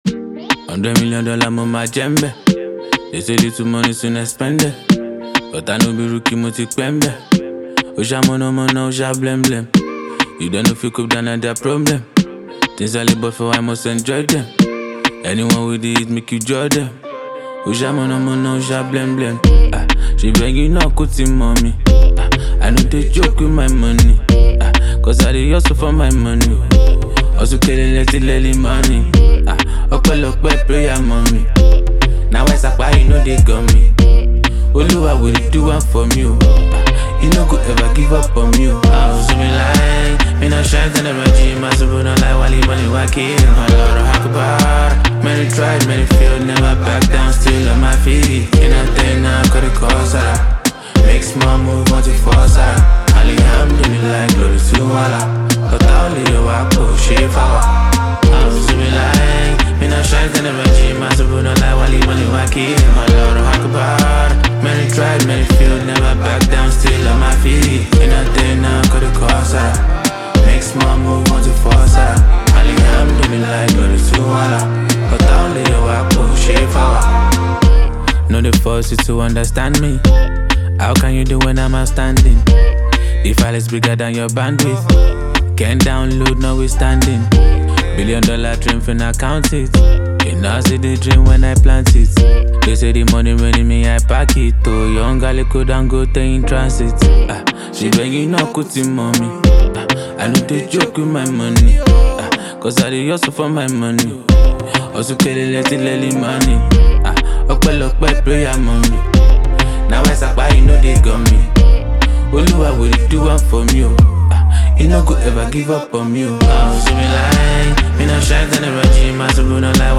Nigerian rap
blending soulful melodies with raw lyricism.